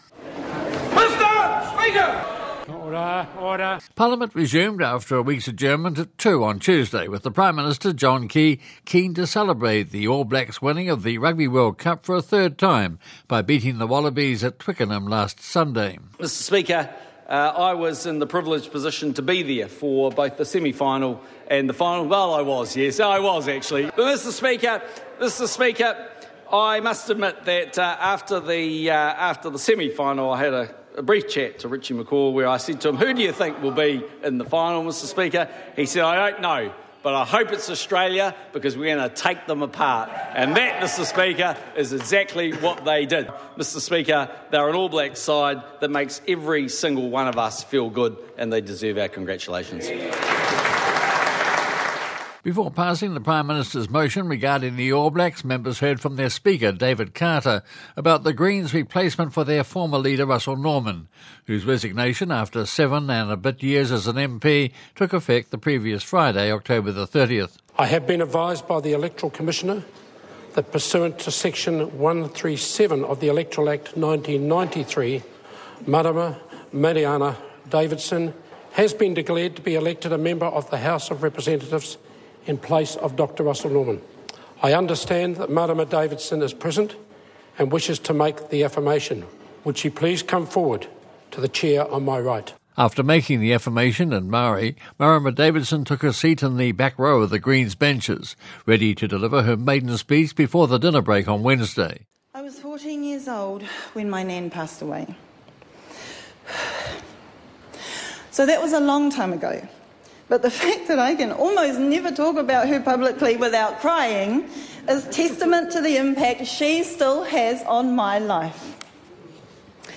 new-green-mp-delivers-maiden-speech.mp3